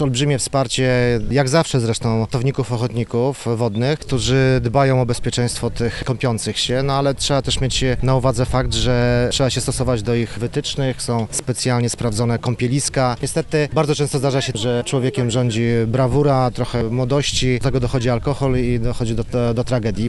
Na ratownictwo wodne samorząd województwa przekazał ponad 2 miliony złotych między innymi na zakup nowego sprzętu ratowniczego, mówi wicemarszałek Rafał Rajkowski: